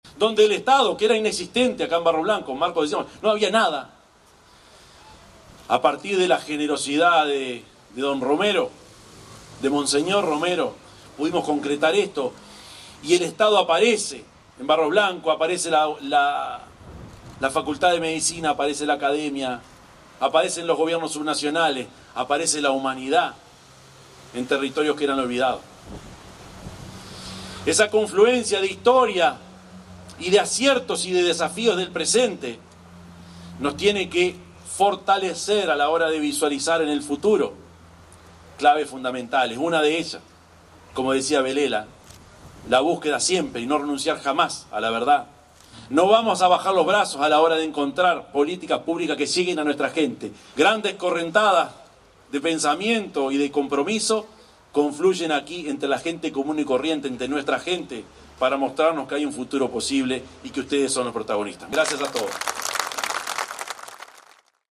En Barros Blancos se realizó acto conmemorativo a 50 años del Golpe de Estado en Chile
intendente_orsi_1.mp3